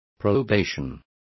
Complete with pronunciation of the translation of probations.